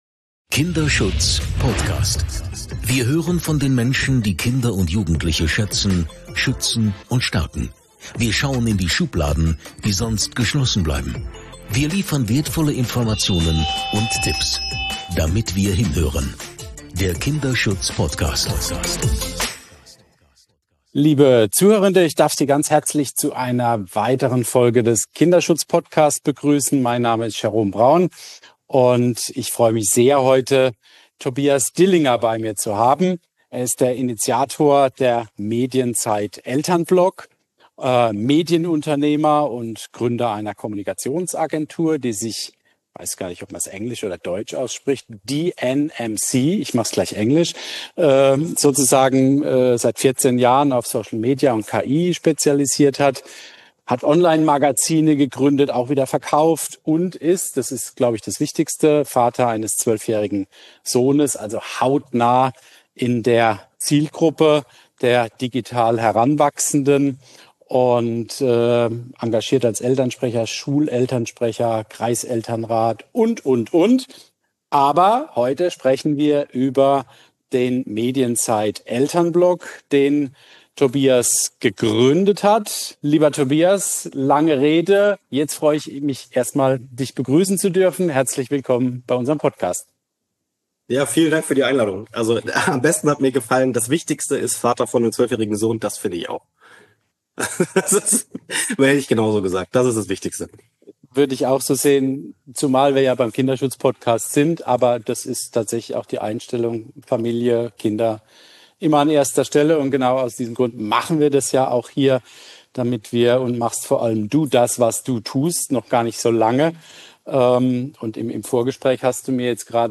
Wir reden über geheime Zweitkonten auf Instagram, über Australien, die Soziale Medien für unter 16-Jährige jetzt einfach sperren und wir zeigen, was ein 13-jähriges Kind wirklich zu sehen bekommt, wenn es sich bei TikTok anmeldet. Ein Gespräch über die digitale Realität der Kinder und die elterliche Orientierung, in dieser digitalen Welt, die Kinder den Algorithmen schutzlos ausliefert.